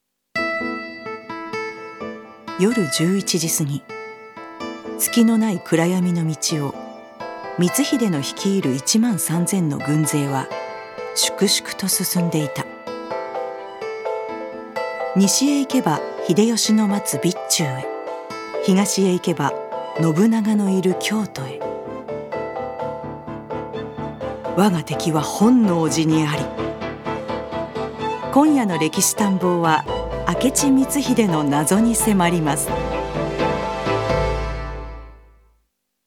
女性タレント
ナレーション８